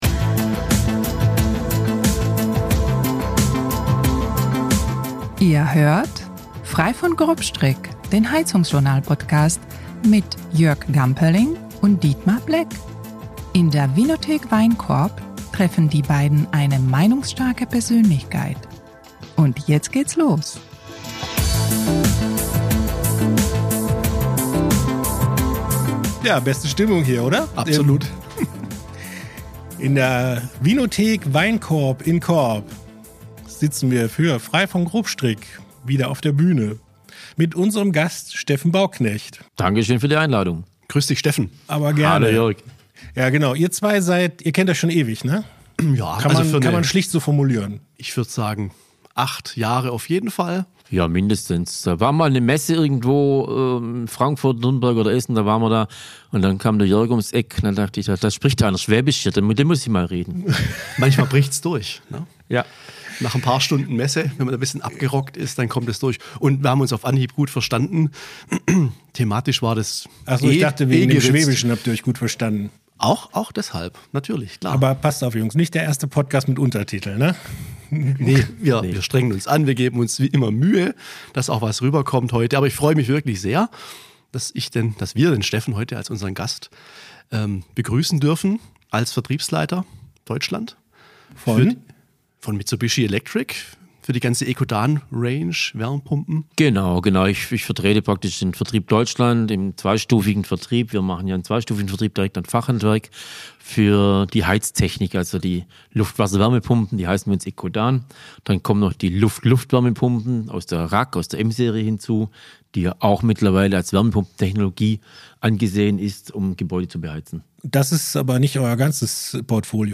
Als er das Tuch lüftet, entsteht kurz Unruhe am Tisch.
Locker bis launig, meinungsstark und informativ, das ist Frei von Grobstrick, der HeizungsJournal-Podcast.